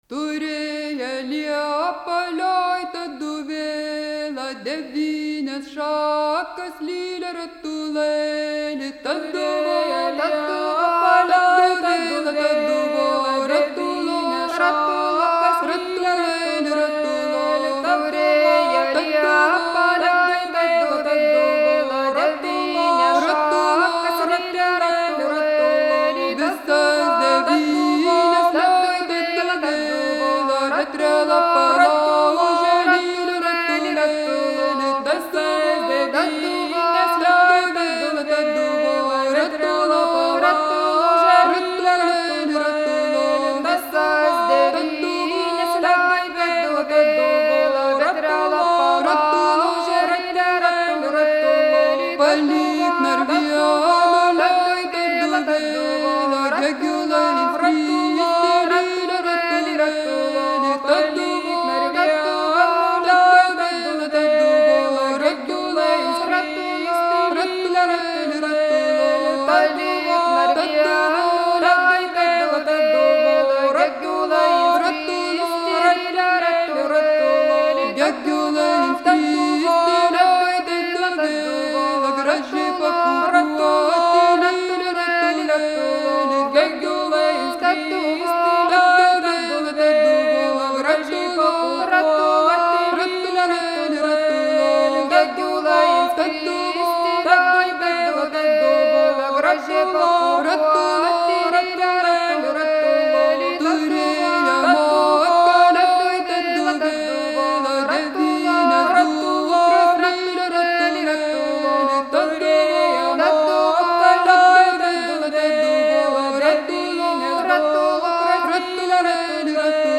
Sutartinės
All three singers perform both phrases of the melody at staggered intervals, continuing until they have sung the entire text of the song.